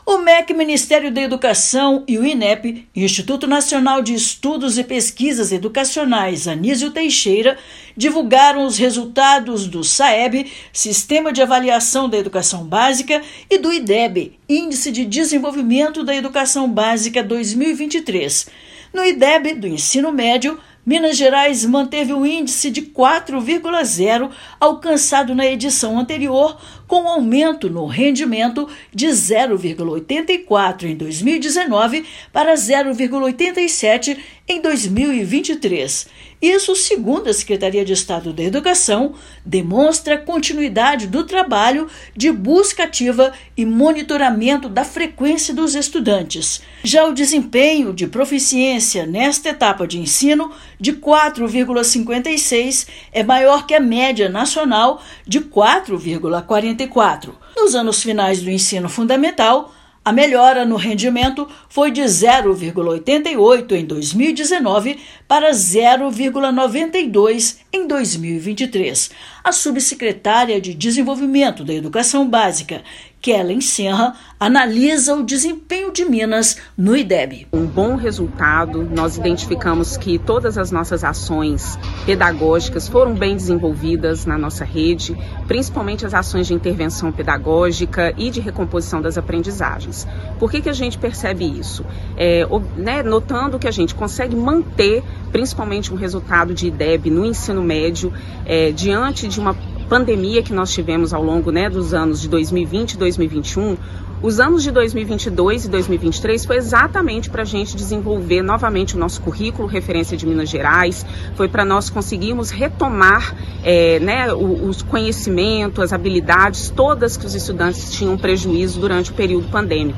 Resultado apresenta a primeira avaliação nacional em larga escala após o período pandêmico; rede estadual de ensino mantém índice histórico do ensino médio. Ouça matéria de rádio.